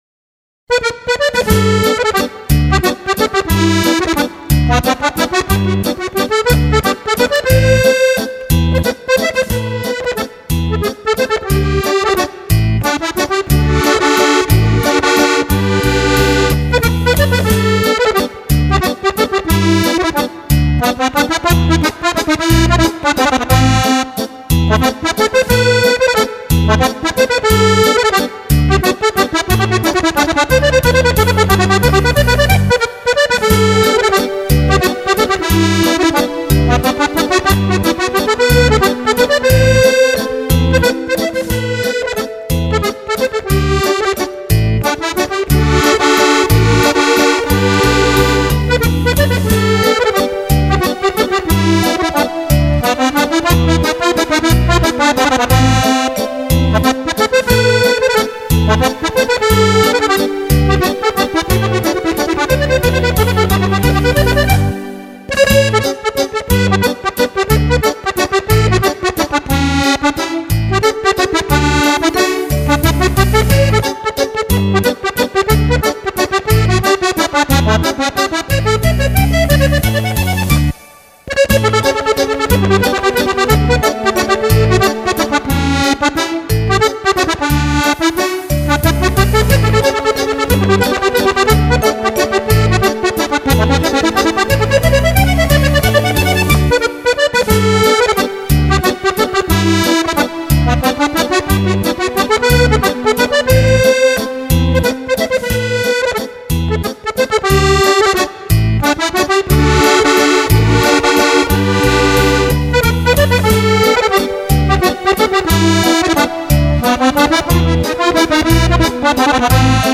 Valzer Musette